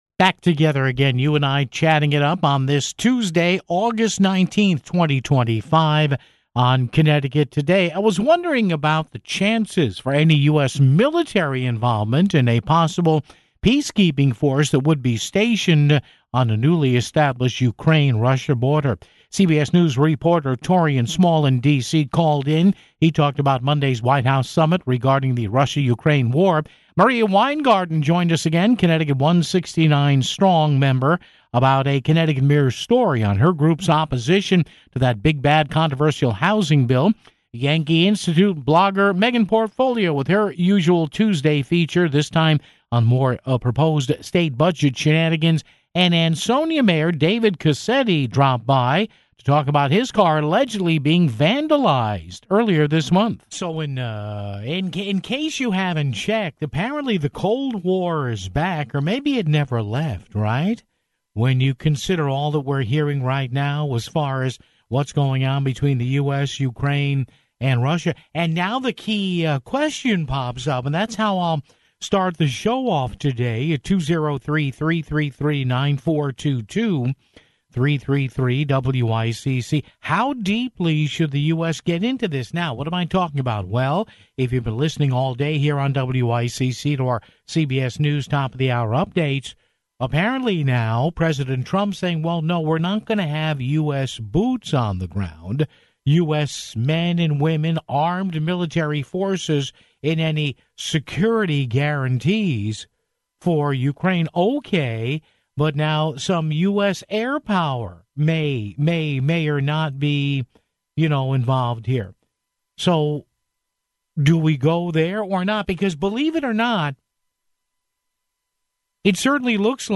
Ansonia Mayor David Cassetti talked about his car allegedly being vandalized earlier this month (39:59)